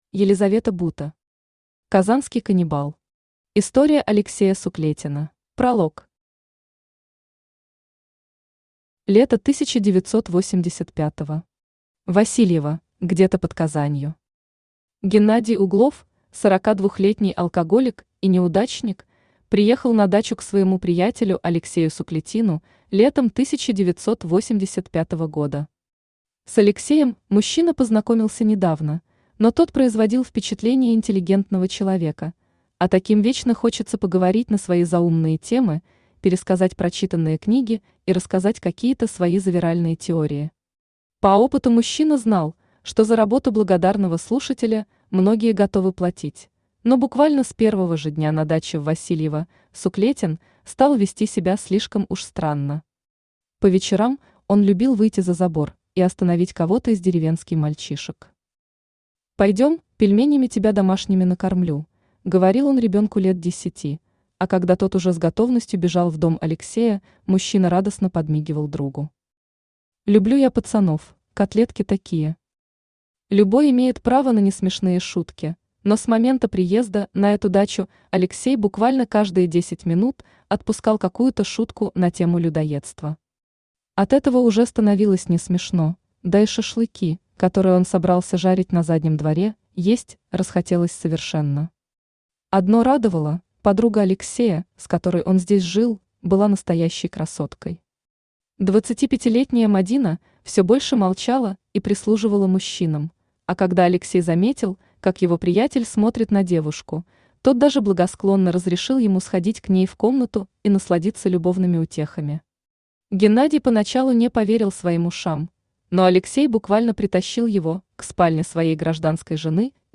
История Алексея Суклетина Автор Елизавета Бута Читает аудиокнигу Авточтец ЛитРес.